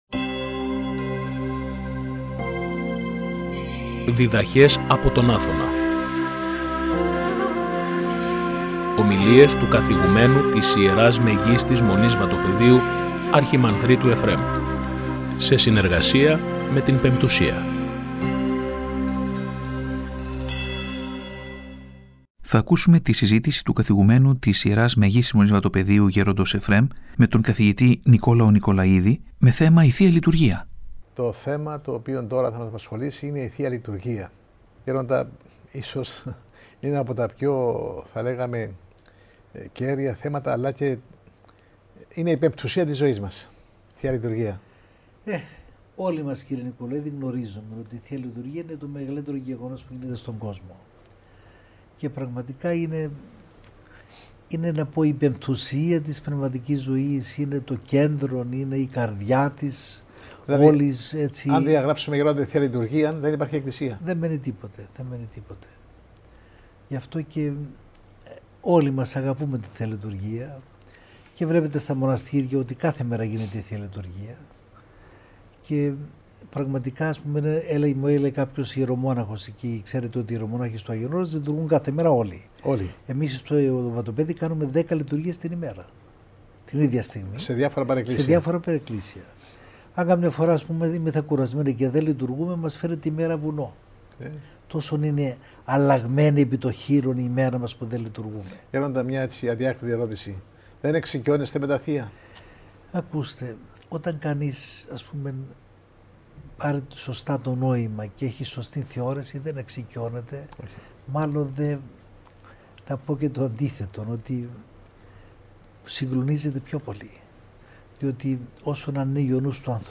Ομιλία του Καθηγουμένου της Ι.Μ.Μ. Βατοπαιδίου Γέροντος Εφραίμ με θέμα: «Η Θεία Λειτουργία», η οποία μεταδόθηκε στην εκπομπή «Διδαχές από τον Άθωνα» στη συχνότητα του Ραδιοφωνικού Σταθμού της Πειραϊκής Εκκλησίας την Κυριακή 05 Ιανουαρίου 2025.